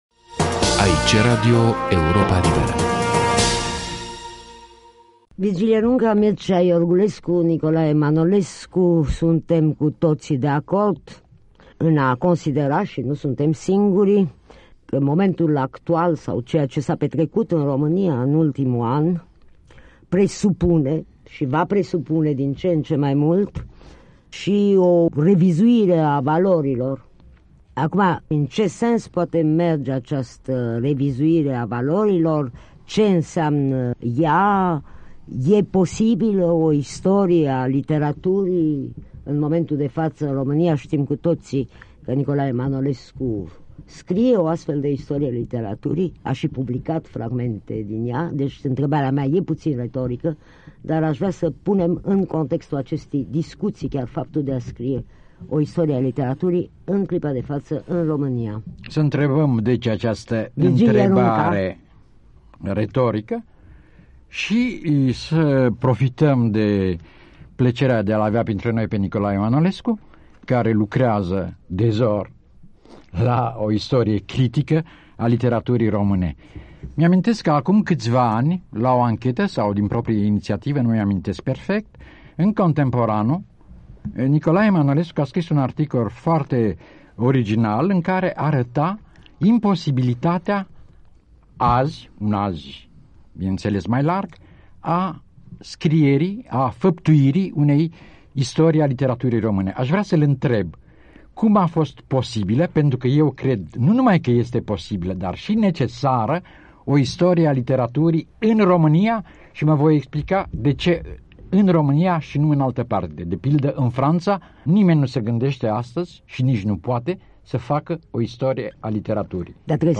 Fragment dintr-o masă rotundă difuzată la radio Europa Liberă în 1991.